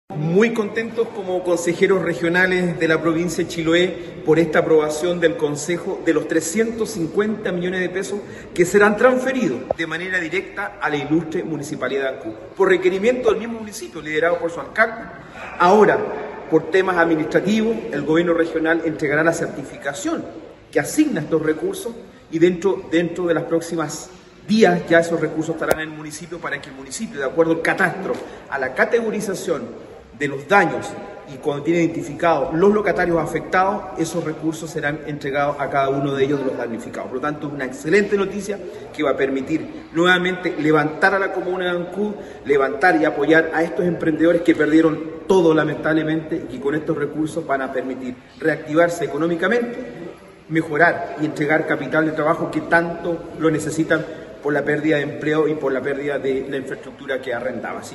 El presidente de la Comisión Provincial Chiloé del Core, Francisco Cárcamo, expresó su satisfacción por la aprobación de estos recursos.